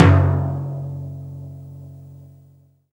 44_28_tom.wav